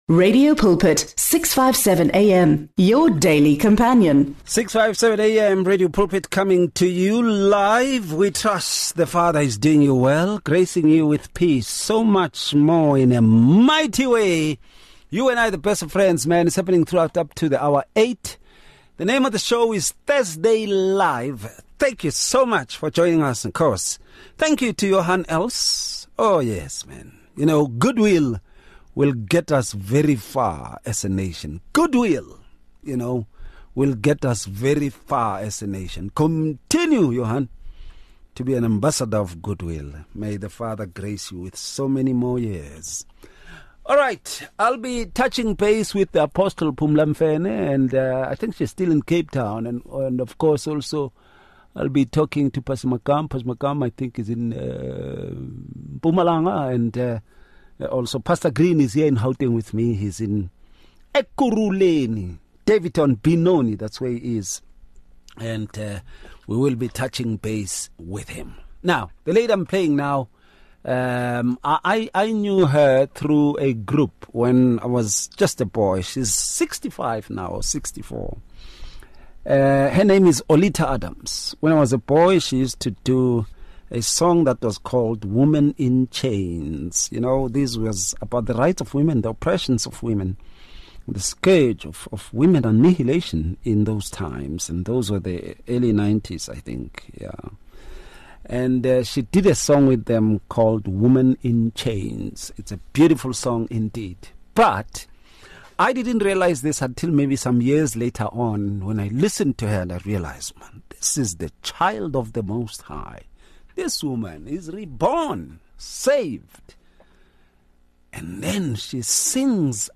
The panel examines foundational principles of discipleship, emphasizing its importance in nurturing spiritual growth and community engagement. Through thoughtful dialogue, they address challenges and strategies for effective discipleship within contemporary church settings.